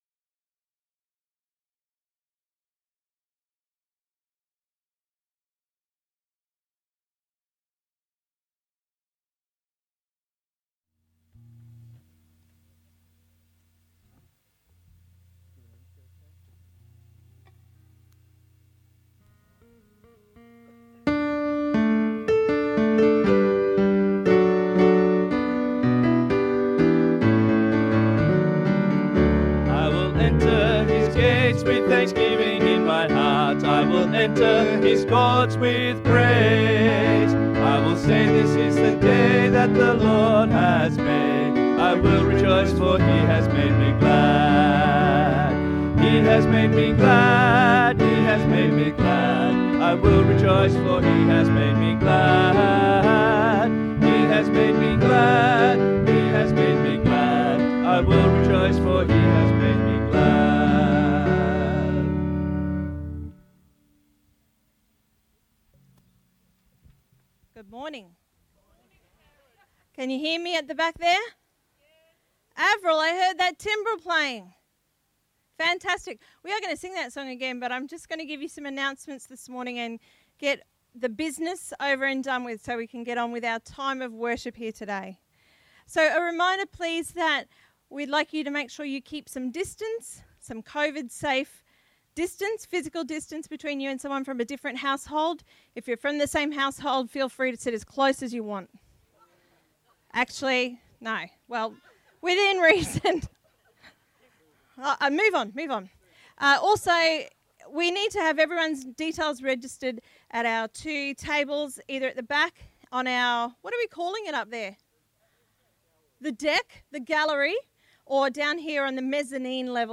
Sunday_Meeting_9th_August_2020_Audio.mp3